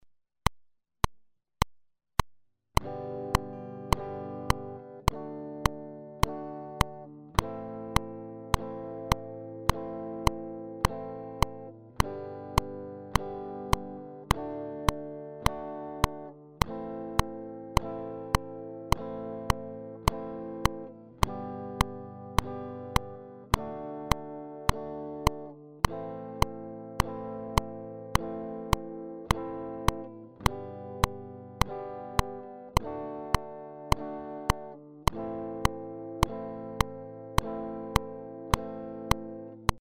Then speed up the chords once every bar.